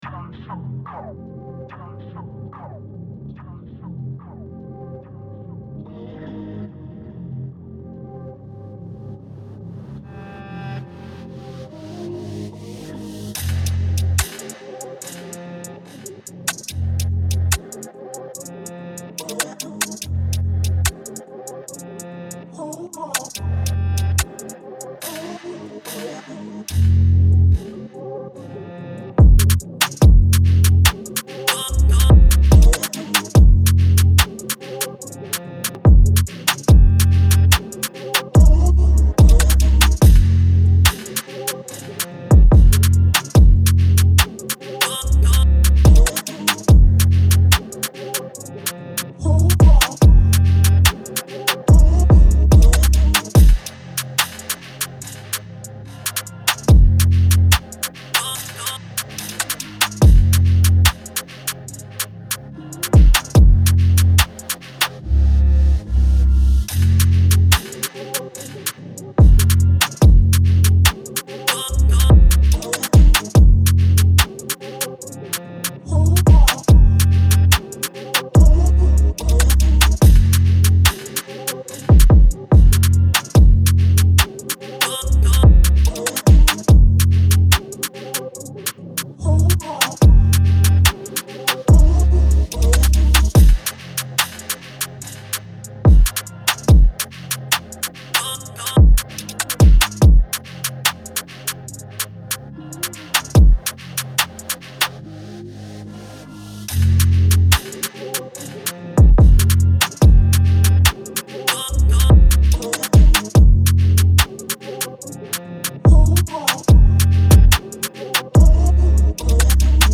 It is UK Drill with 144 BPM in Fmin scale.